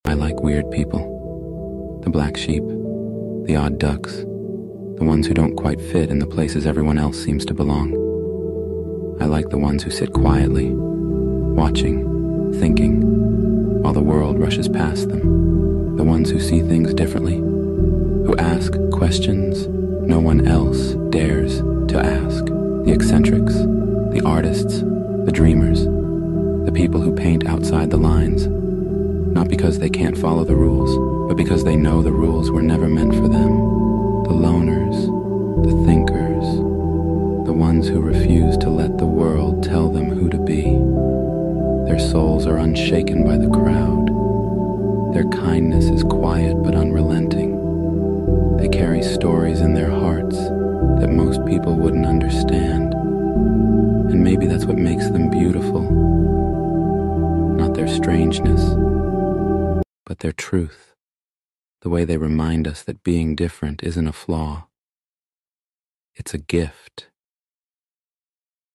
Fairground Games & Prizes sound effects free download
Fairground Games & Prizes - Hale Village Carnival